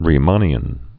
(rē-mänē-ən)